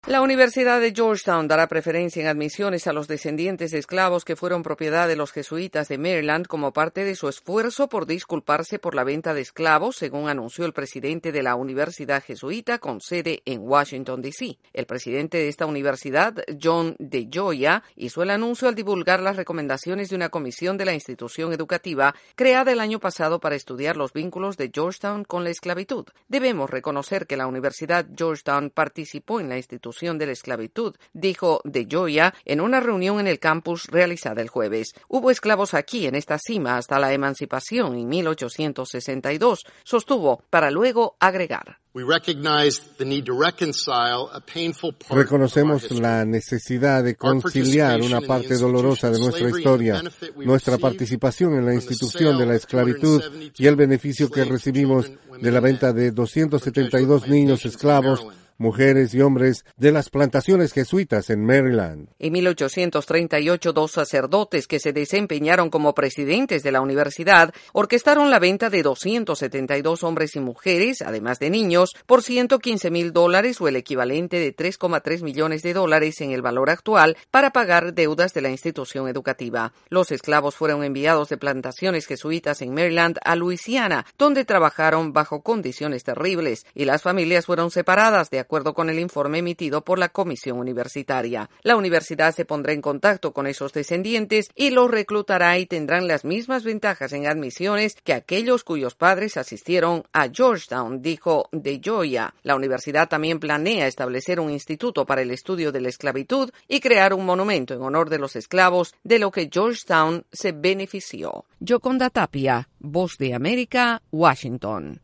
La prestigiosa Universidad de Georgetown se disculpa por la venta de esclavos en 1838 y ofrece compensar a los descendientes de esas personas. Desde la Voz de América en Washington DC informa